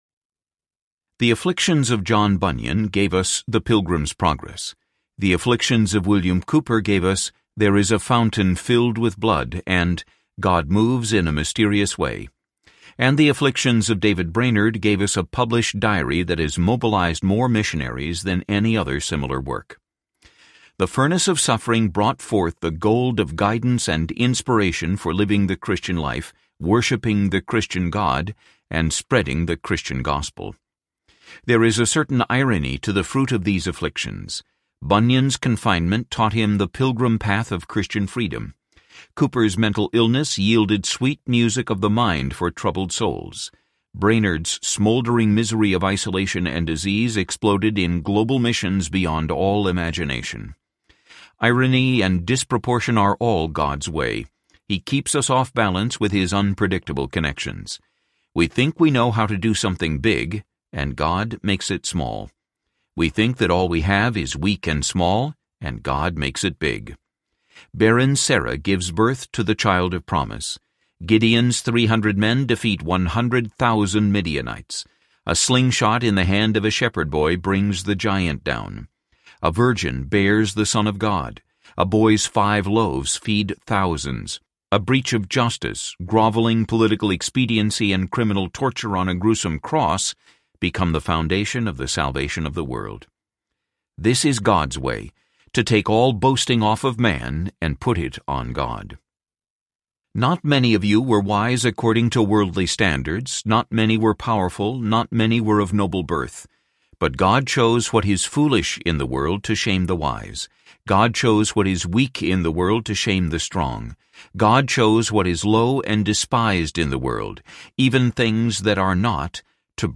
The Hidden Smile of God (The Swans Are Not Silent Series, Book #2) Audiobook
Narrator